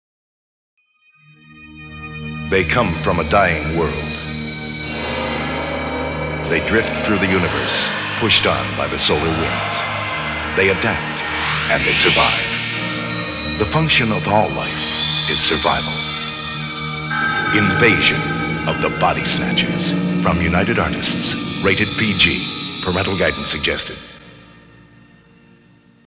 Radio Spots
Here are six spots for the 1978 release…three mono spots and three spots in stereo.